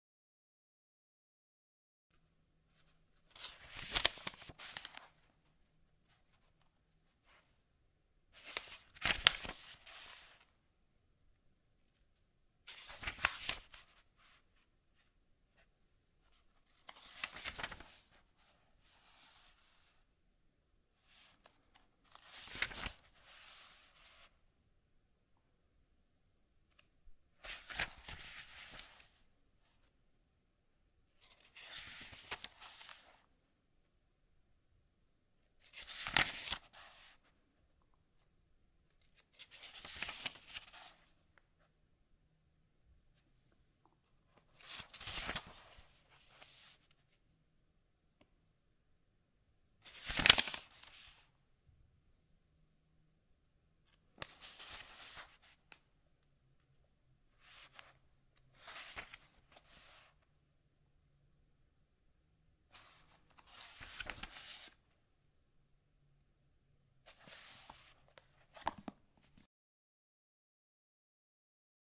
دانلود صدای ورق زدن کتاب 2 از ساعد نیوز با لینک مستقیم و کیفیت بالا
جلوه های صوتی
برچسب: دانلود آهنگ های افکت صوتی اشیاء دانلود آلبوم صدای ورق زدن کتاب، صفحه یا برگه از افکت صوتی اشیاء